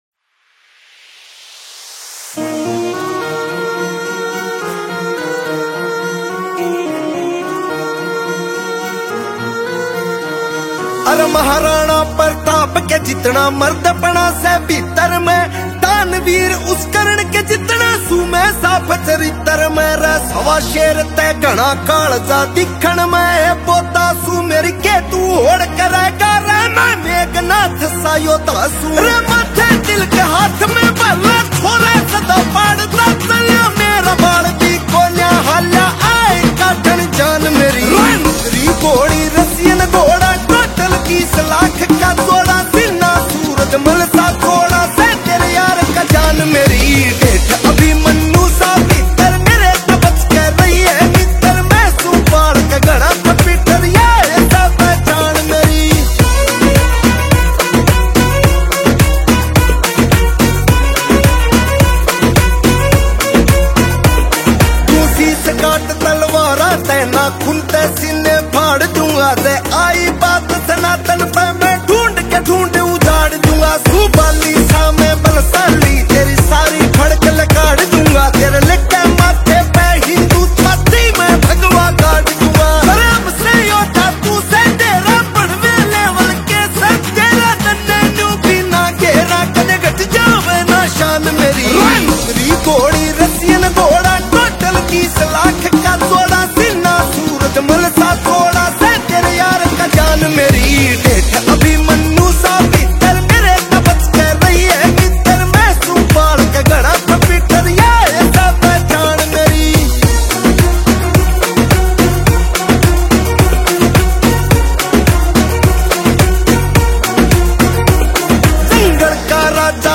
Releted Files Of Latest Haryanvi song